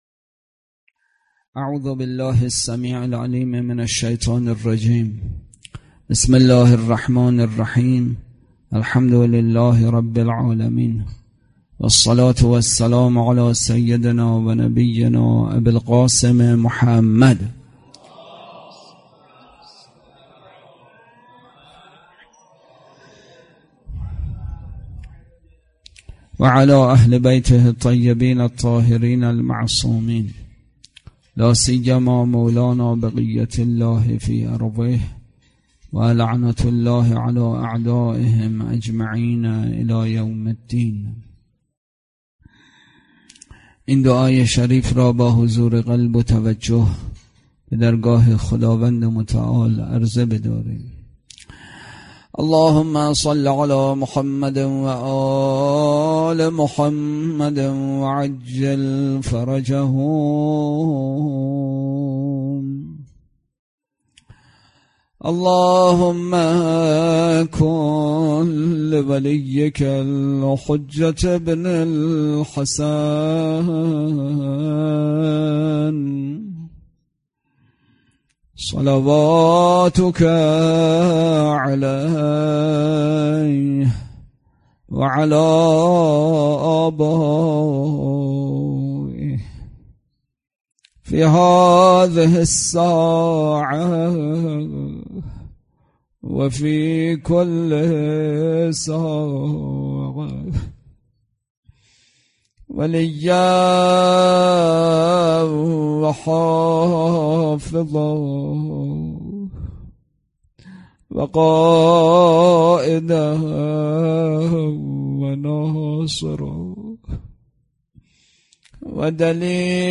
سخنرانی
برگزارکننده: مسجد اعظم قلهک